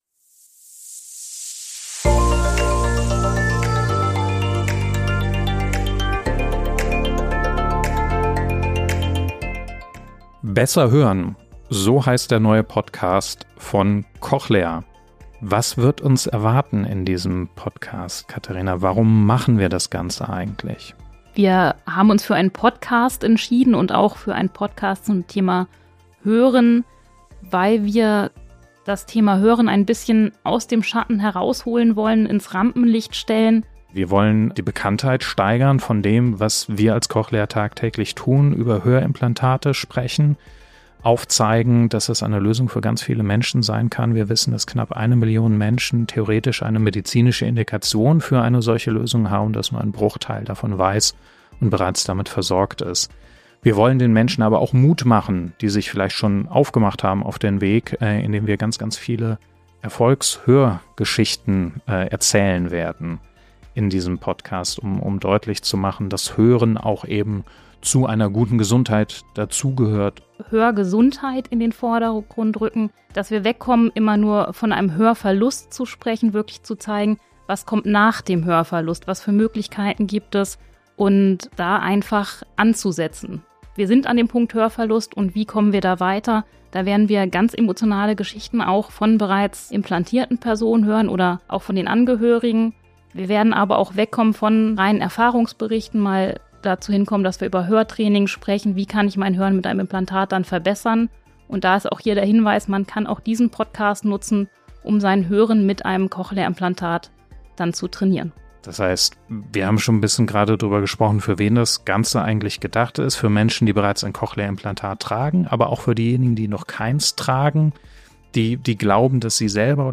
Sie sprechen mit Betroffenen, Medizinier*innen und Hörexperten.